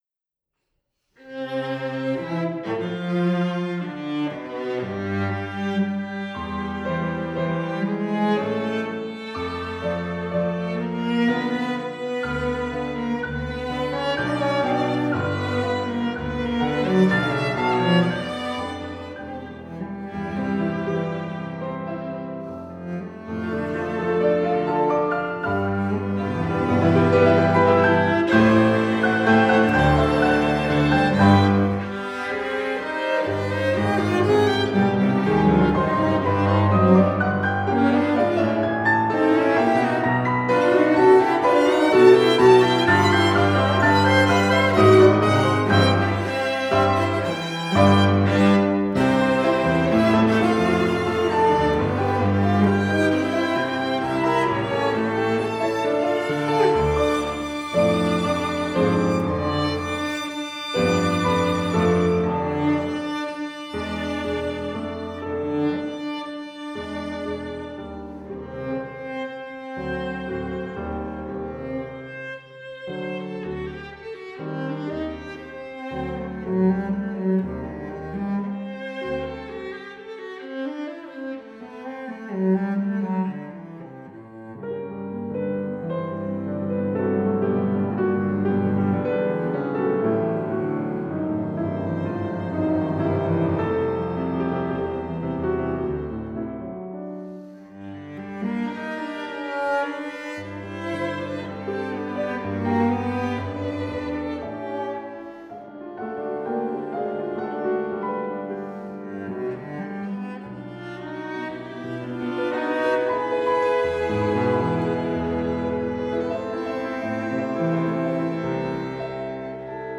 Brahms+-+Piano+Trio+No.2+Op.87+Kuma+Trio.mp3